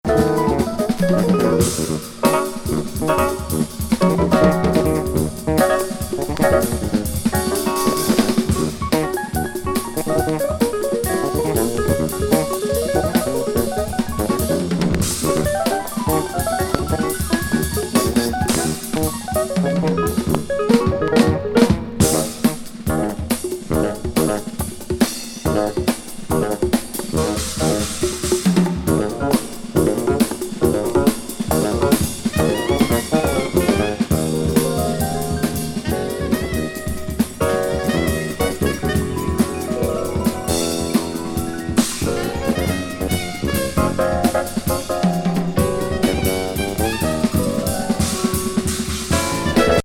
ブラジリアン・ジャズ・インプロ！